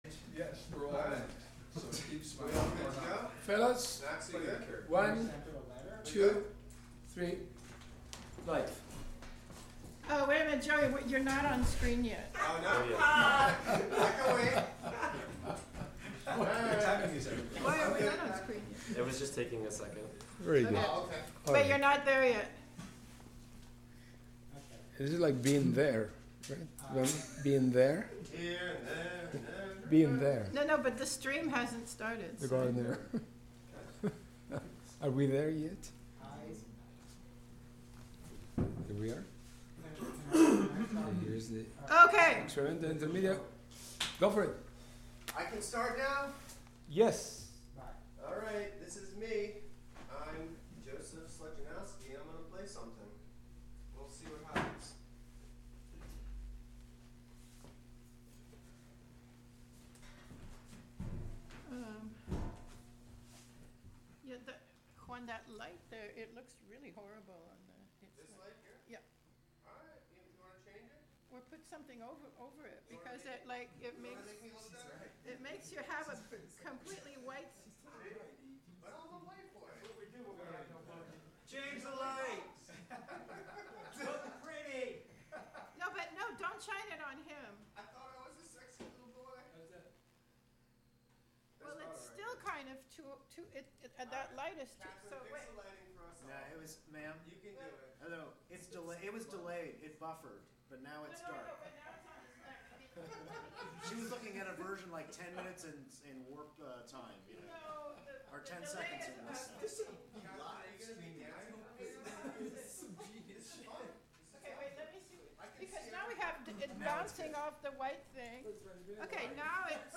Live from Experimental Intermedia